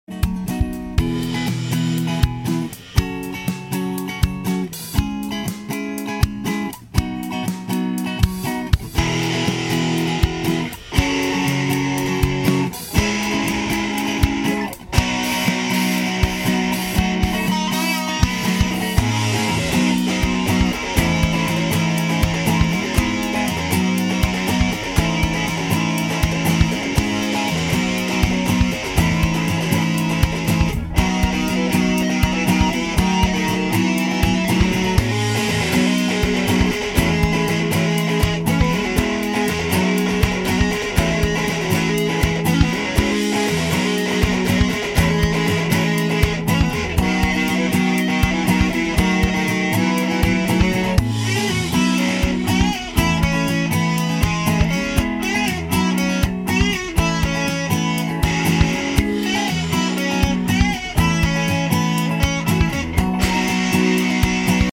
long guitar solo